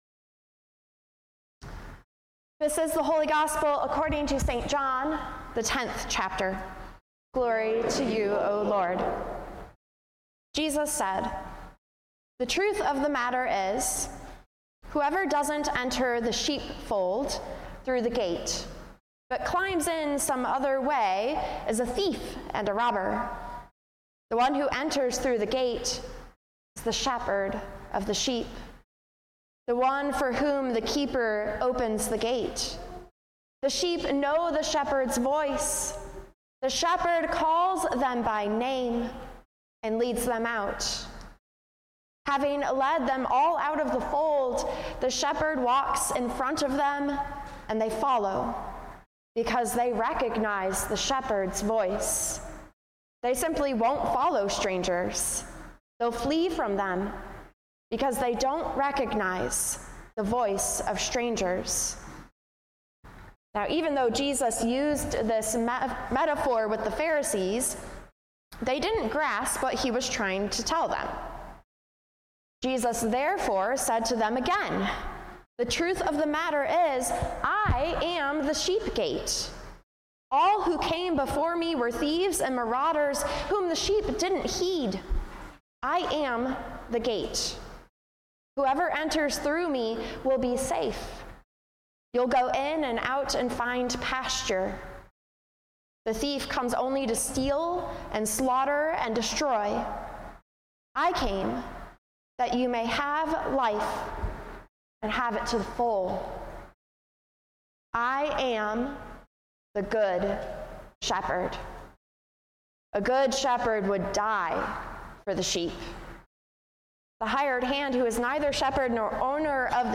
Sermons | Grace Evangelical Lutheran Church